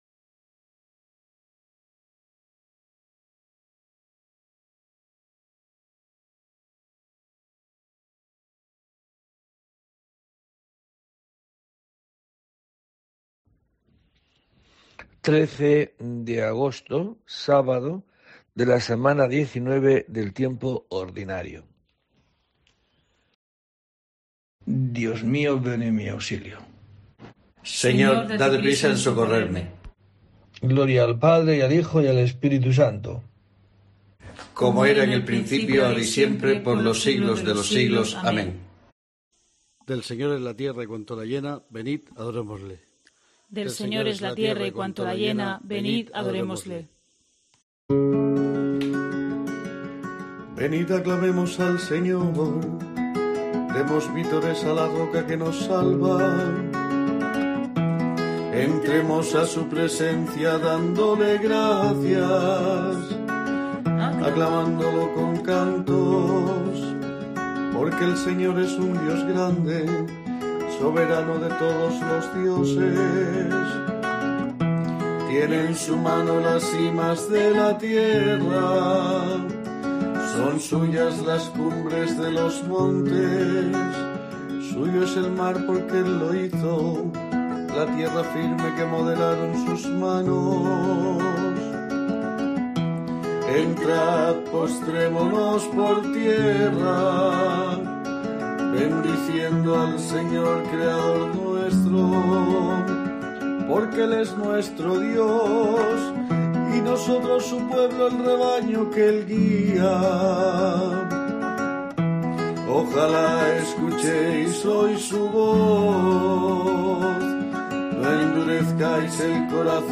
13 de agosto: COPE te trae el rezo diario de los Laudes para acompañarte